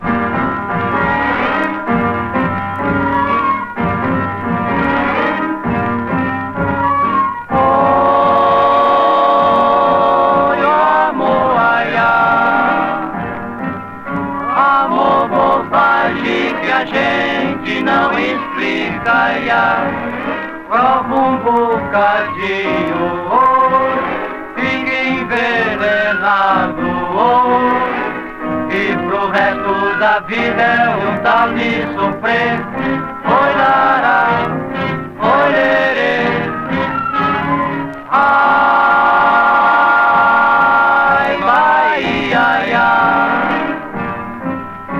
Pop, Vocal, World, Samba　Brazil　12inchレコード　33rpm　Mono